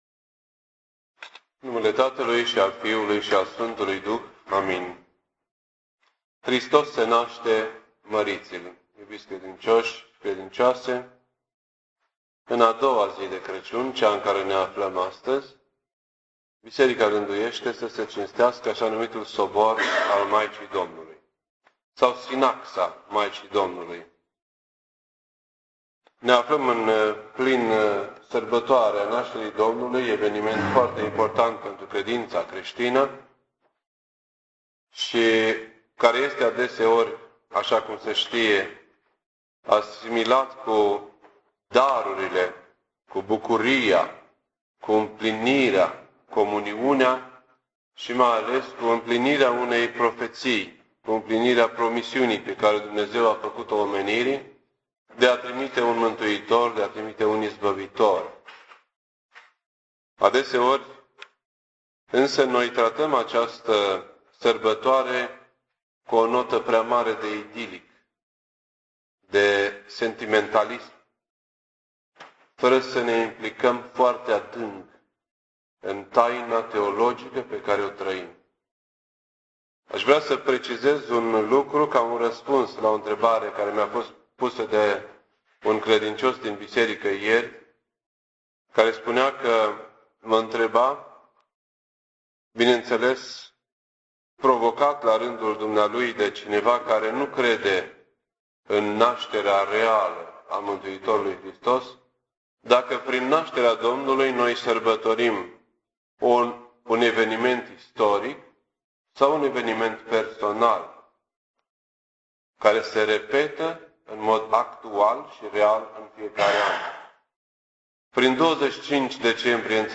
This entry was posted on Wednesday, December 26th, 2007 at 9:57 AM and is filed under Predici ortodoxe in format audio.